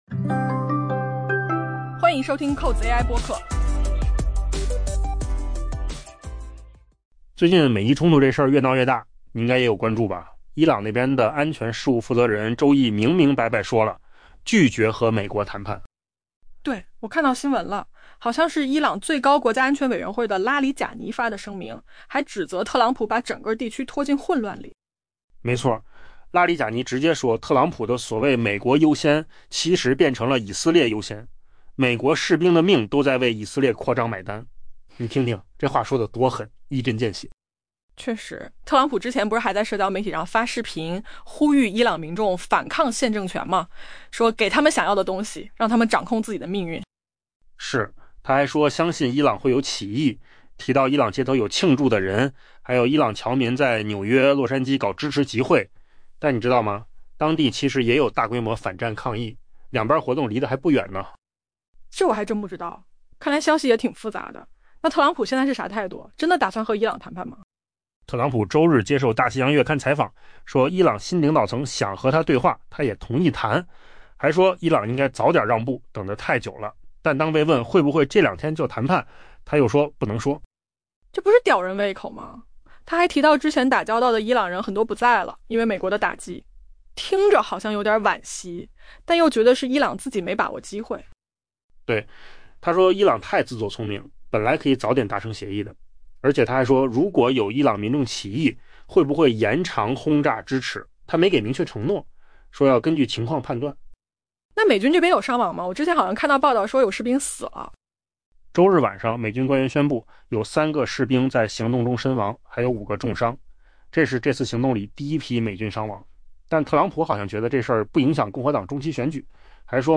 AI 播客：换个方式听新闻 下载 mp3 音频由扣子空间生成 随着美以与伊朗之间的冲突加剧， 德黑兰的安全事务负责人周一明确拒绝了与华盛顿的谈判。